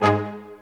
BRASSHTG#3.wav